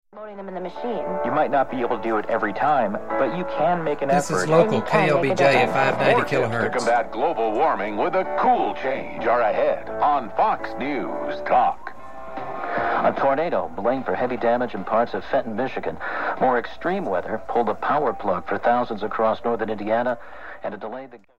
Local reception drives speaker to room filling volume using no extra amplification-
click here to listen  (This recording was made with a stereo microphone placed two feet from the radio speaker)
Local-KLBJ-at-night.mp3